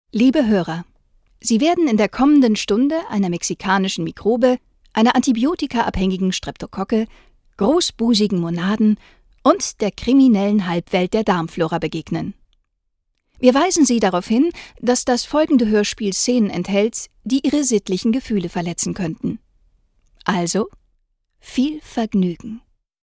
Erfahrene vielseitige Sprecherin. Stimmalter zwischen jung und mittel einsetzbar. Klare deutliche Stimme-
Sprechprobe: Sonstiges (Muttersprache):